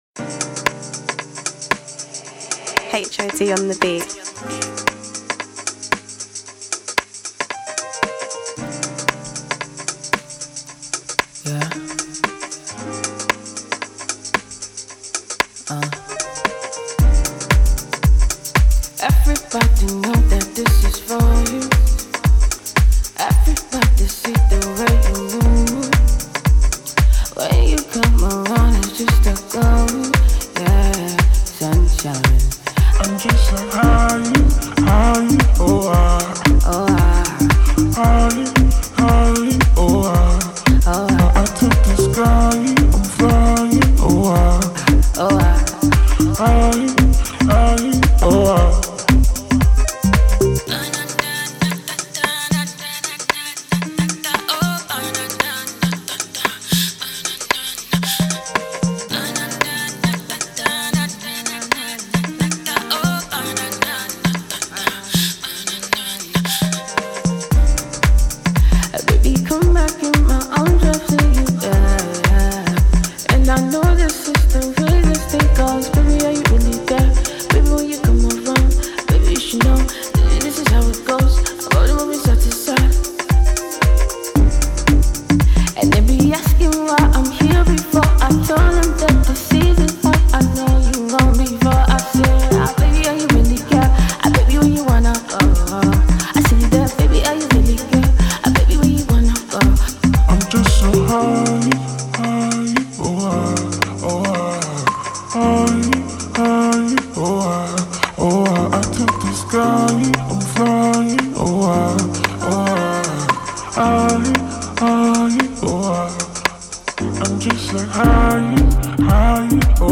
ecstatic, feel good, music